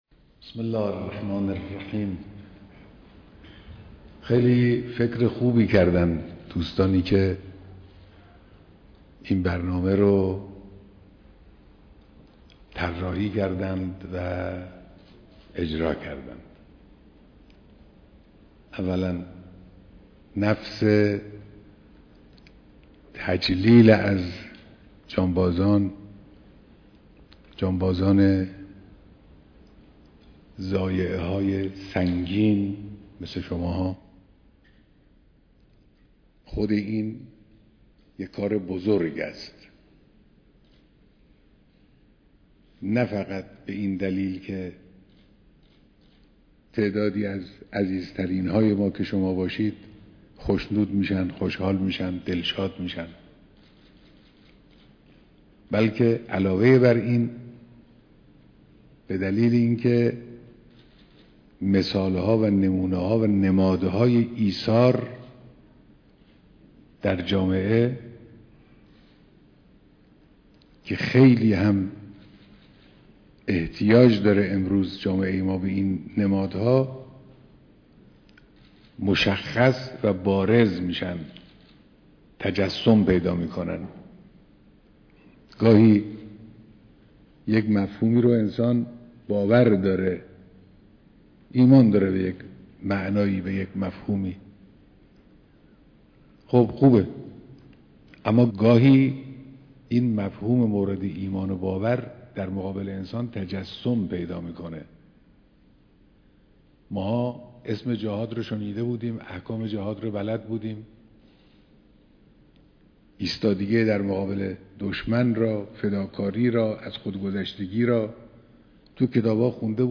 بيانات در ديدار جمعی از جانبازان قطع نخاعى‌